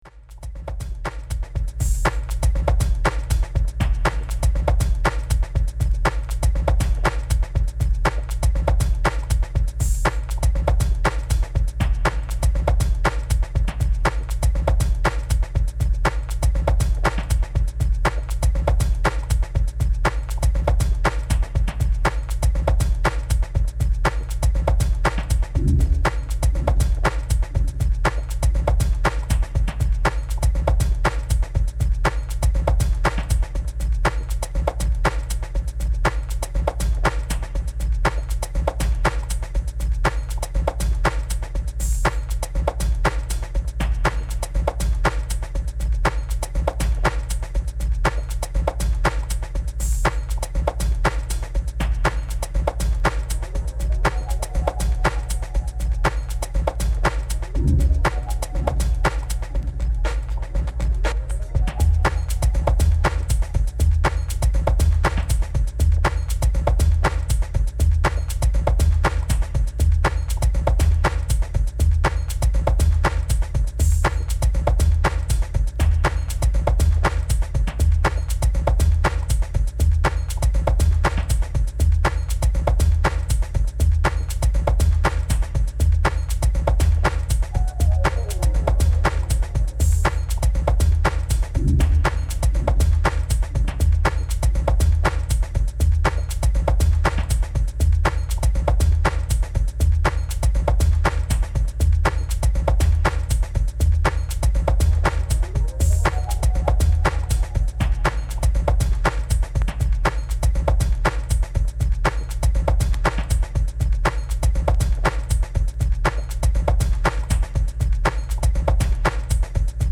House / Techno